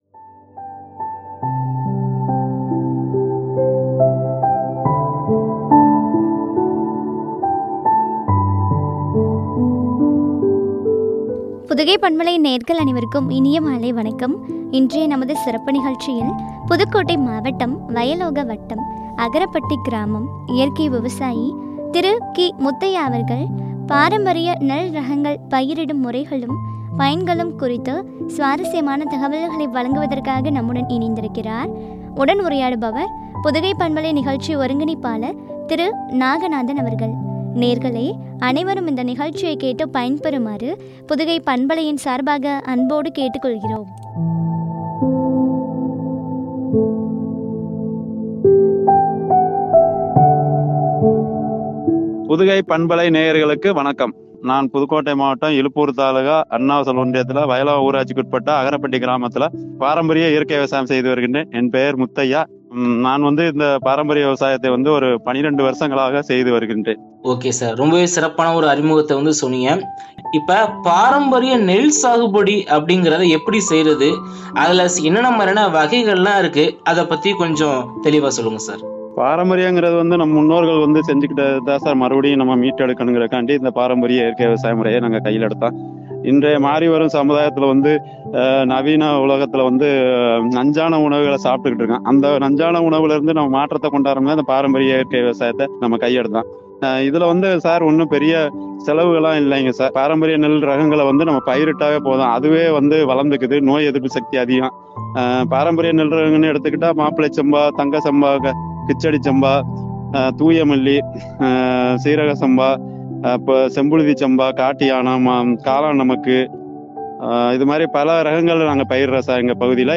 பயன்களும்” குறித்து வழங்கிய உரையாடல்.